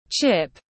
Khoai tây chiên tiếng anh gọi là chip, phiên âm tiếng anh đọc là /tʃɪp/
Chip /tʃɪp/